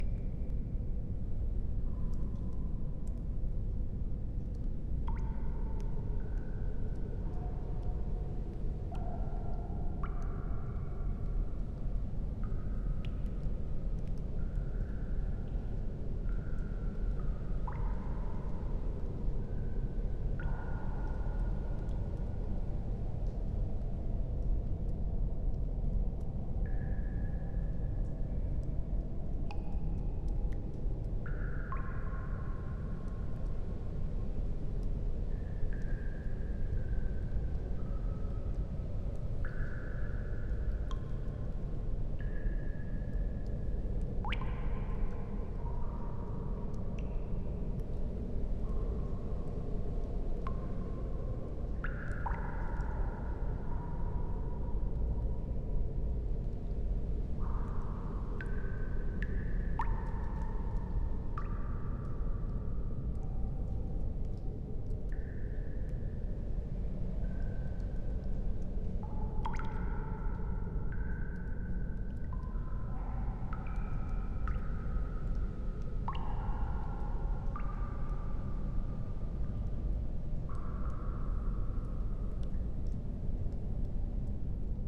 Cave.ogg